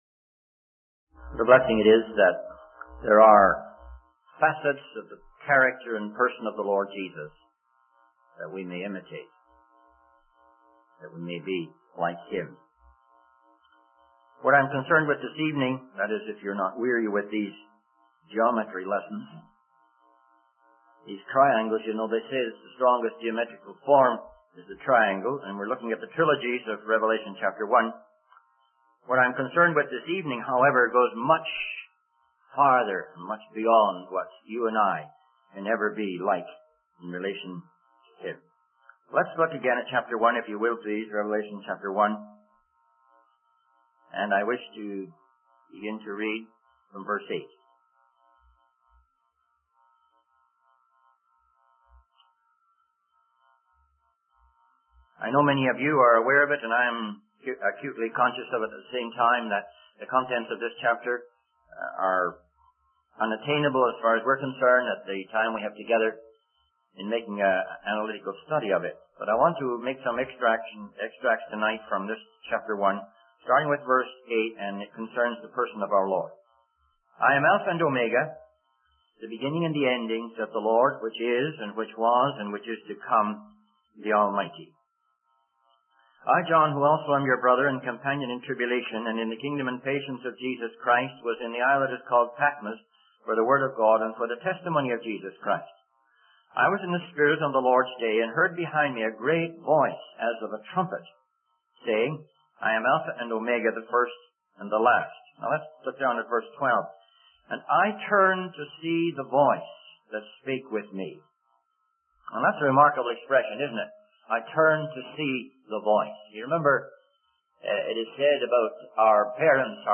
In this sermon, the speaker addresses the concerns and difficulties faced by society, such as immorality, injustice, and violence.